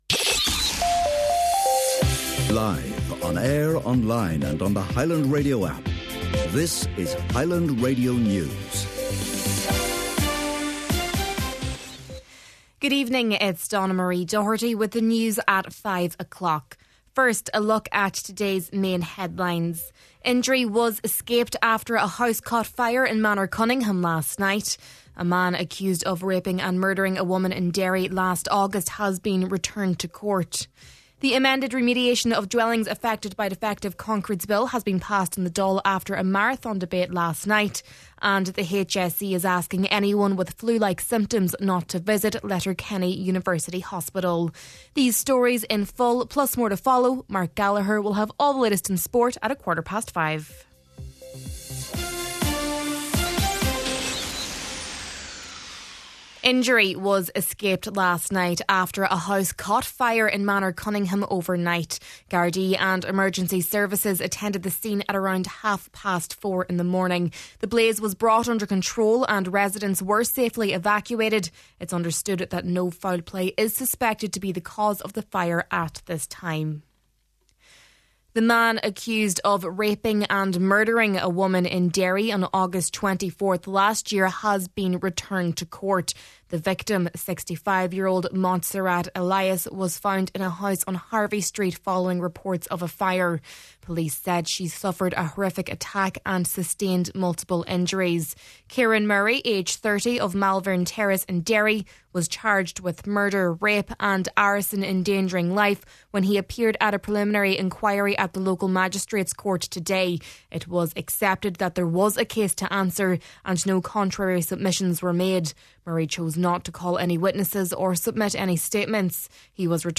Main Evening News, Sport, Farming News and Obituary Notices – Thursday, December 11th